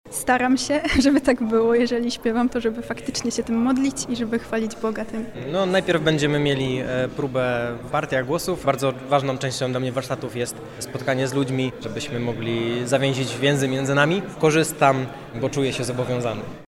-Na miejscu integrujemy się ze sobą – mówią uczestnicy poprzednich warsztatów.